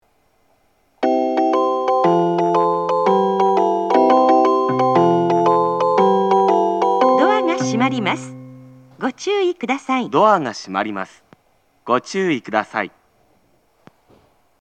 社家駅　発車メロディー混線
スピーカーが上下兼用なのでこのように時々被ります。